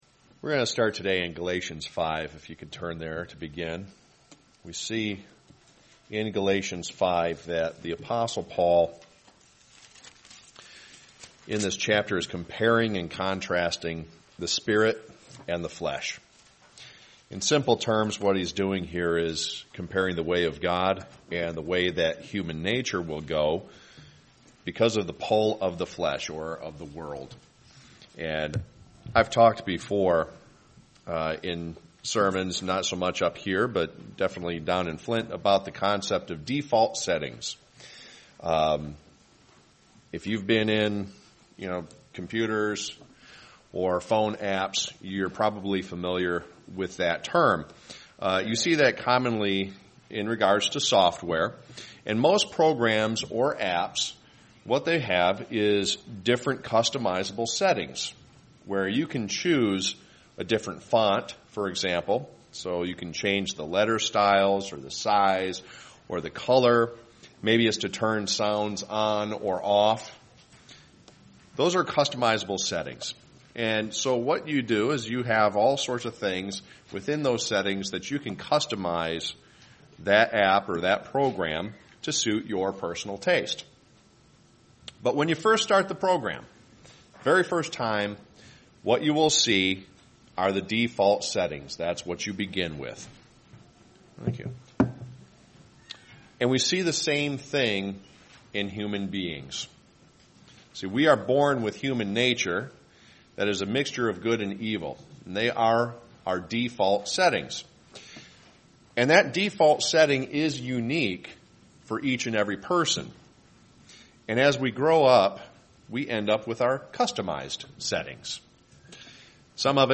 Sermons
Given in Freeland, MI